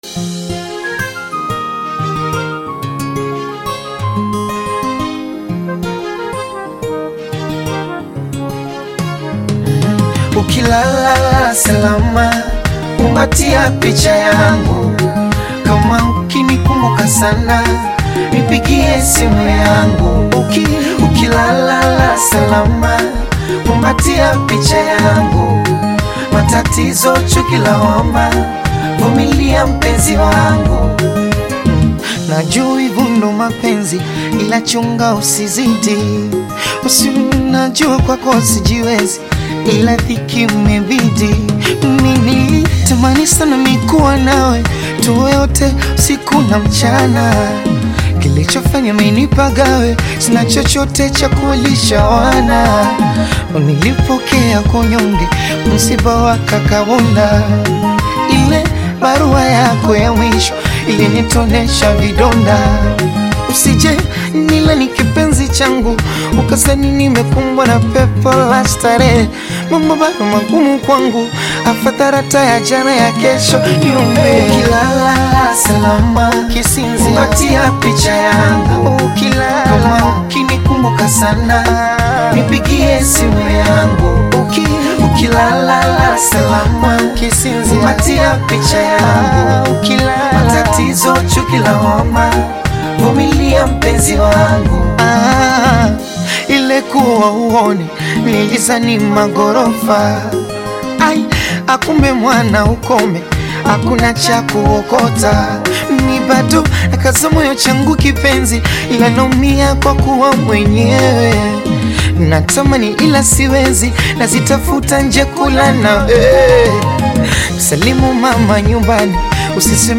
Afro-Pop single
blending smooth vocals and emotive Swahili lyrics about love
Genre: Bongo Flava